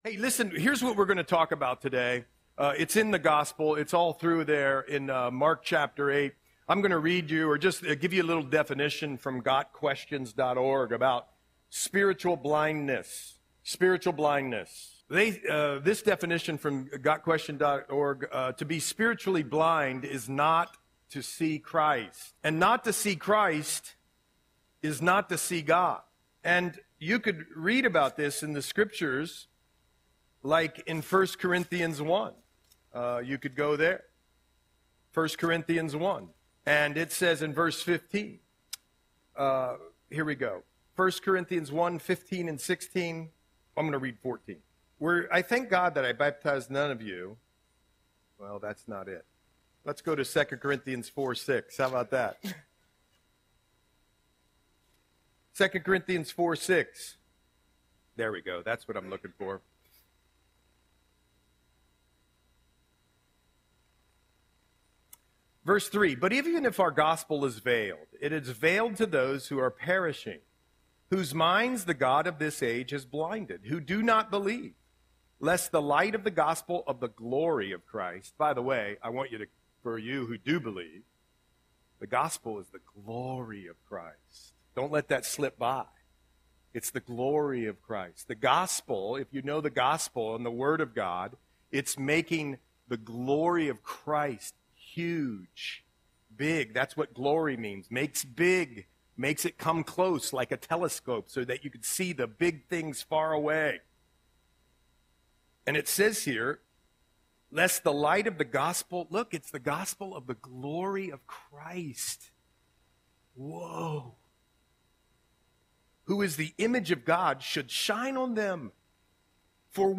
Audio Semon - January 12, 2025